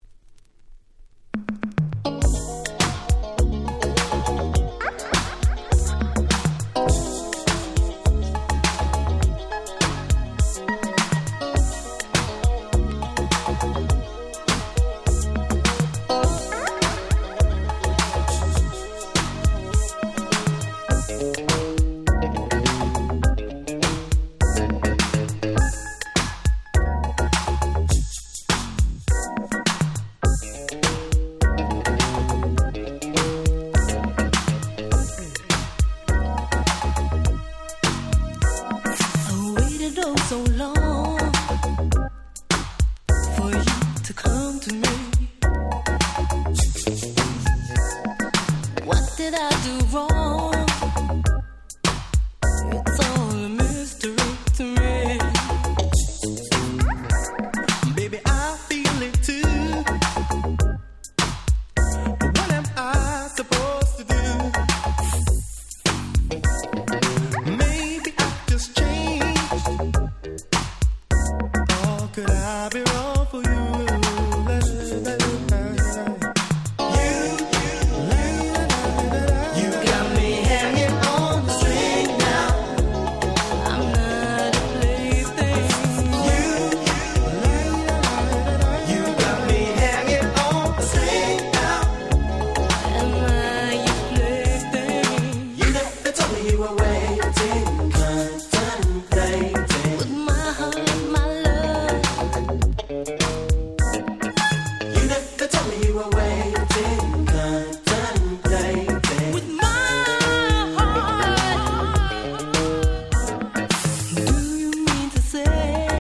最高のUK Soul / Disco Boogieです。